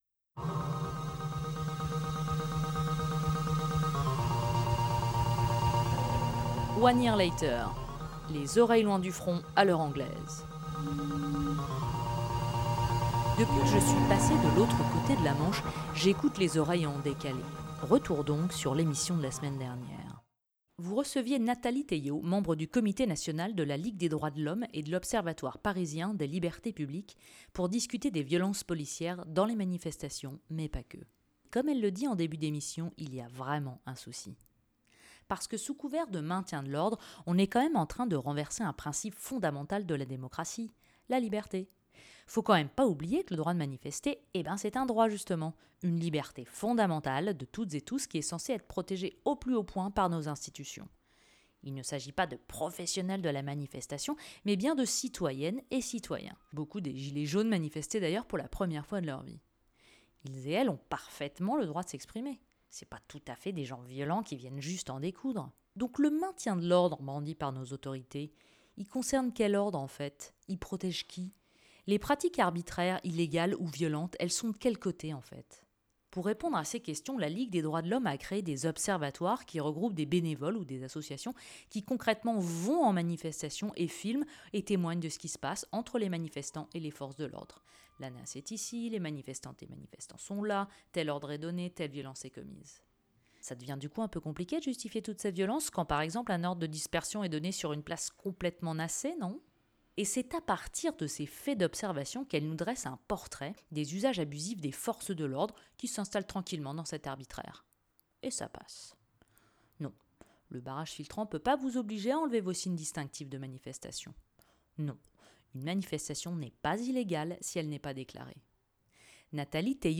Emission radiophonique en direct tous les mercredis de 19h à 20H30